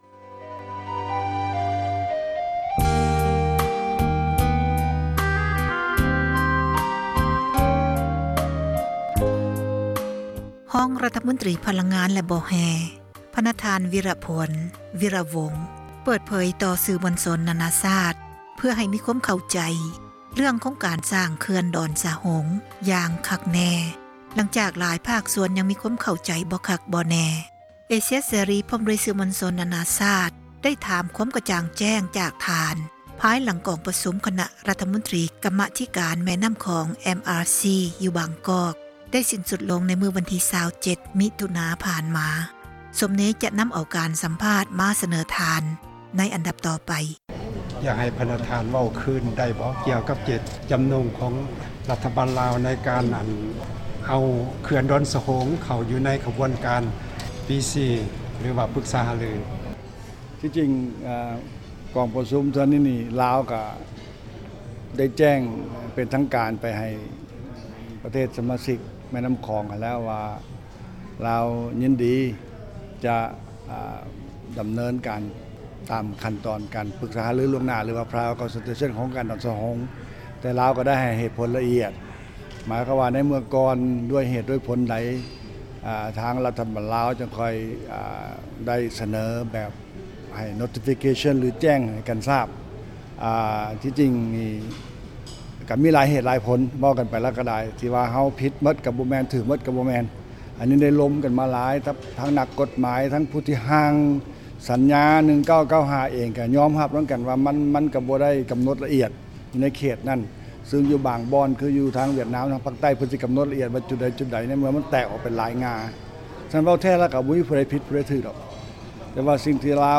ສັມພາດທ່ານວິຣະພົນ ວິຣະວົງ
F-Viraphonh ທ່ານ ວິຣະພົນ ວີຣະວົງ ພາຍຫລັງ ກອງປະຊຸມ ຄນະຣັຖມົນຕຣີ ກັມມາທິການ ແມ່ນໍ້າຂອງ ຢູ່ບາງກອກ ໄດ້ສິ້ນສຸດລົງ ວັນທີ 27 ມິຖຸນາ 2014
ທີ່ທ່ານໄດ້ ຮັບຟັງ ຈົບໄປນັ້ນ ເປັນສ່ວນນຶ່ງ ຂອງ ການຕອບ ຄໍາຖາມ ຂອງ ເອເຊັຽເສຣີ ເທົ່ານັ້ນ, ແຕ່ການໃຫ້ ສັມພາດ ທັງໝົດ ແກ່ ສື່ມວນຊົນ ນານາຊາຕ ໃນເວລາ ດຽວກັນນັ້ນ ເຮົາຈະນໍາມາ ອອກອາກາດ ໃນຂັ້ນ ຕໍ່ໄປ.